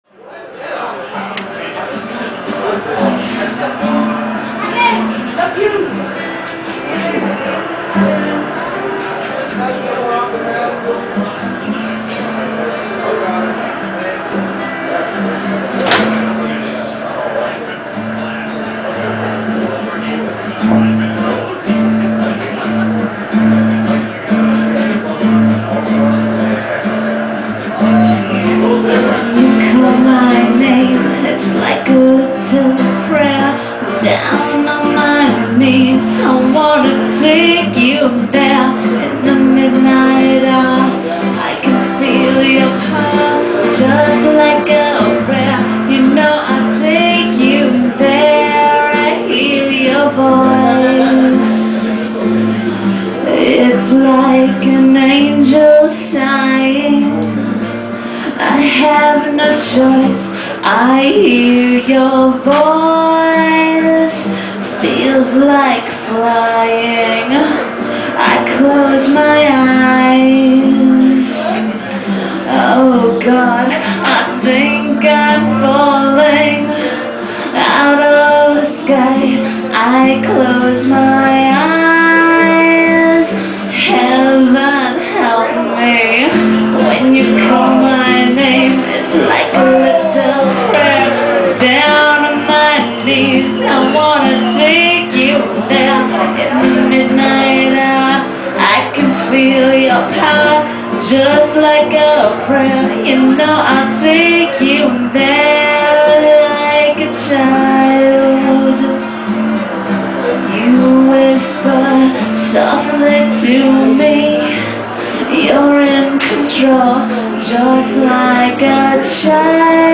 Sung entirely in a harmony voice